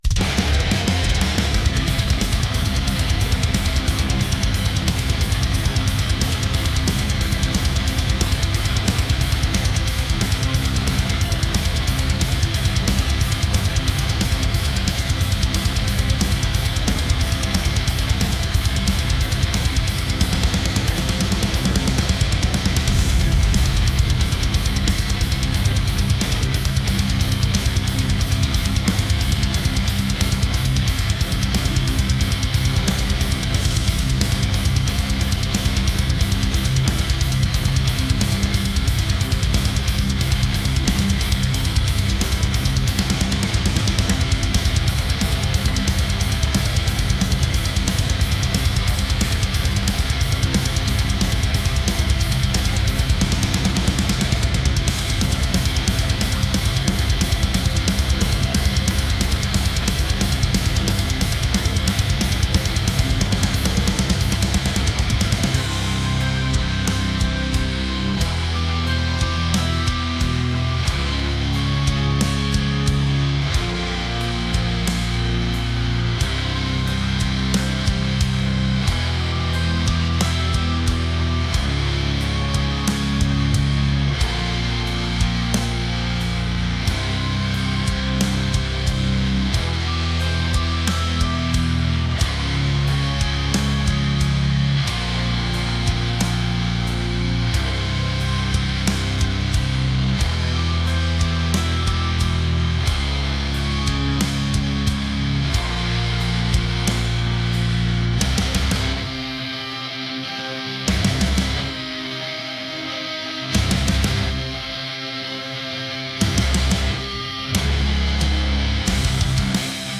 intense | metal | aggressive